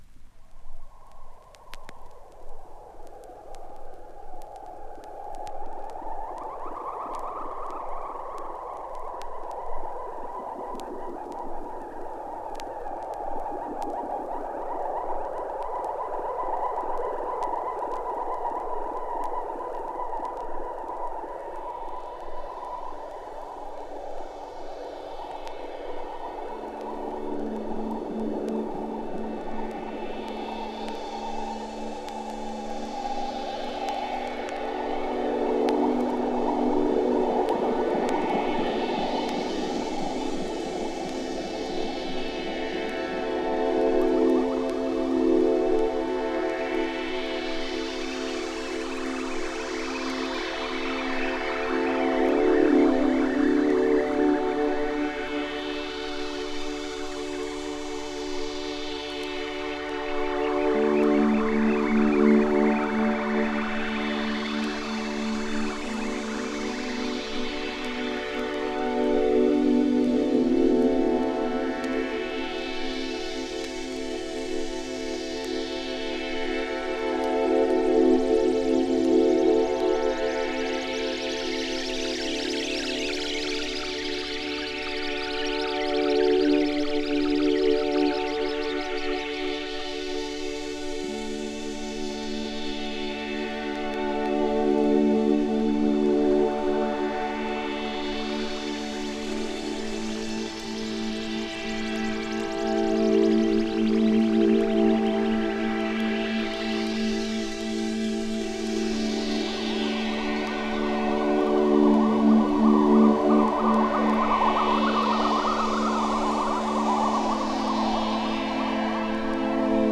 シンセサイザーとシークウェンサーを導入したスペイシー＆コズミックなサウンド。